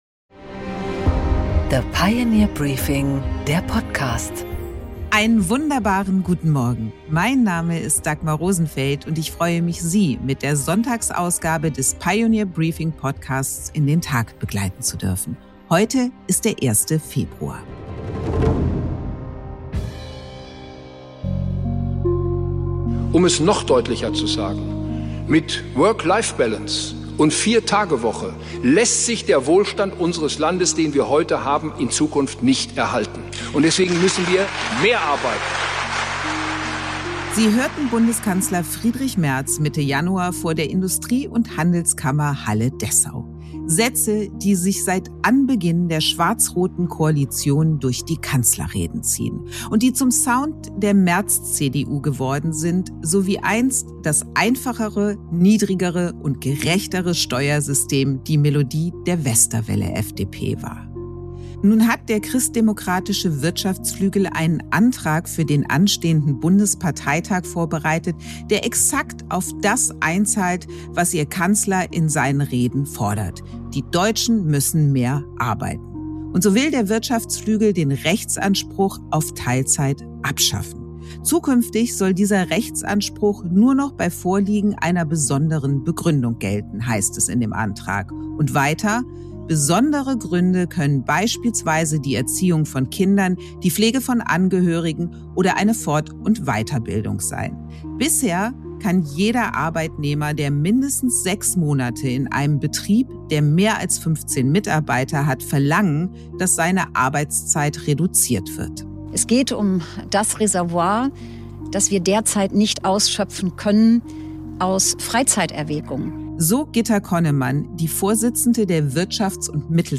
Ein Wort zum Sonntag über Debattenfähigkeit, inhaltliche Ernsthaftigkeit – und den Mut zur Auseinandersetzung.